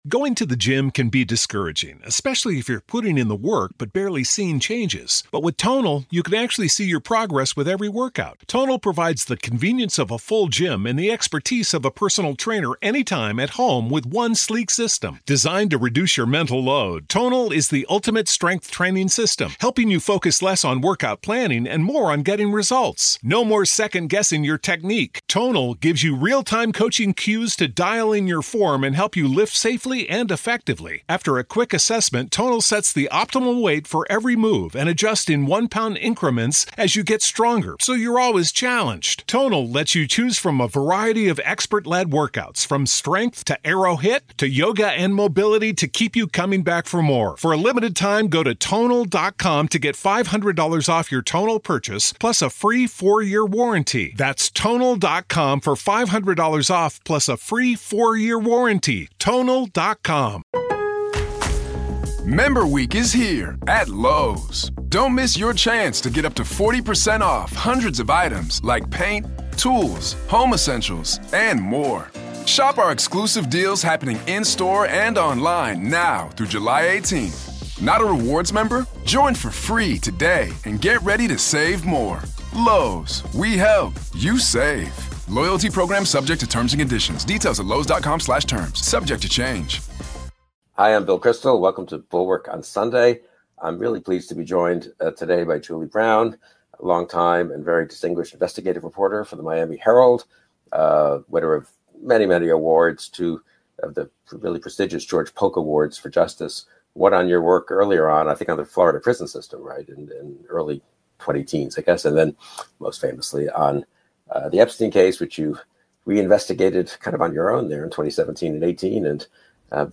Join Bill Kristol and Julie K. Brown on this week's livestream of Bulwark on Sunday. Brown is an investigative journalist and THE preeminent expert on Jeffrey Epstein.